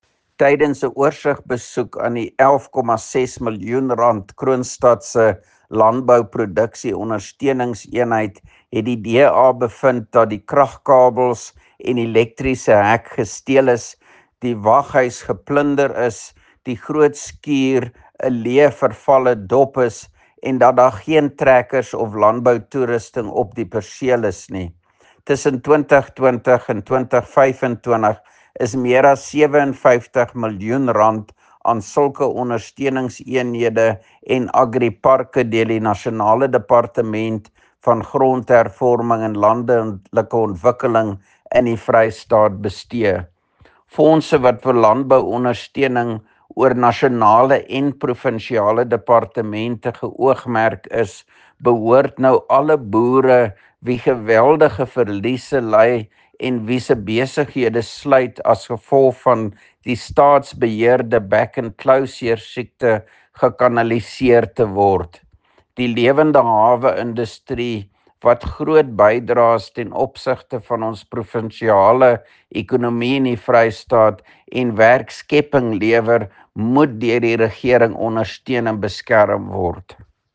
Afrikaans soundbites by Roy Jankielsohn, MPL and